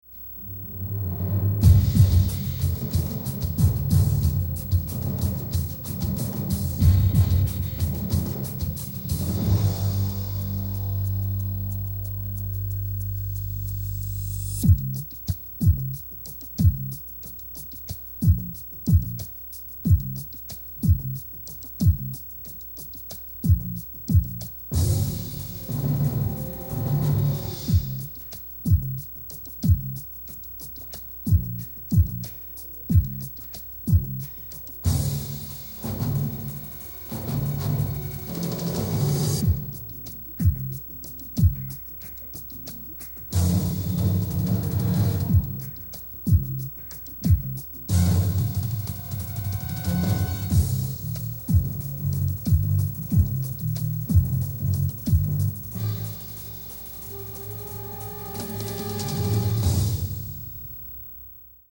[excerpt from the 3-minute end credits suite]